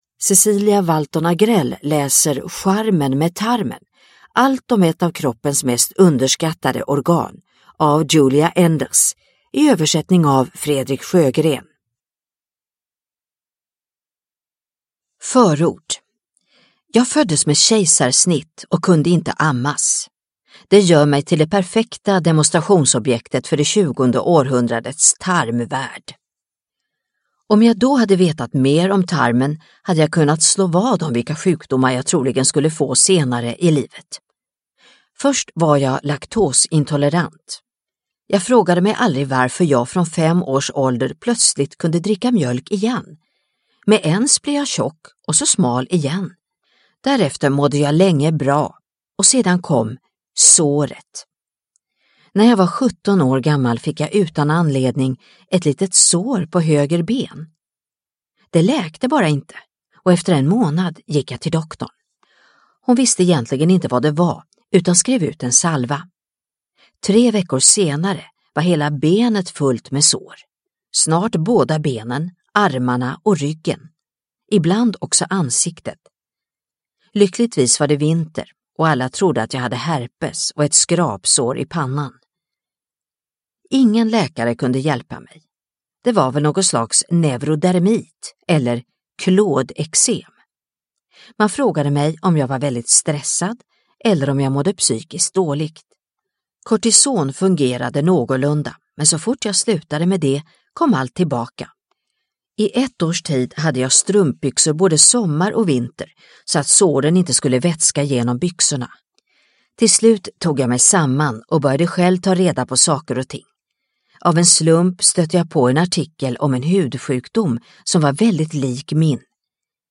Charmen med tarmen : allt om ett av kroppens mest underskattade organ – Ljudbok – Laddas ner